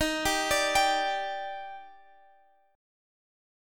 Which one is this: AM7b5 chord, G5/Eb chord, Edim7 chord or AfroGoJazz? G5/Eb chord